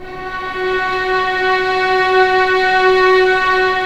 Index of /90_sSampleCDs/Roland LCDP13 String Sections/STR_Violins II/STR_Vls6 p%mf St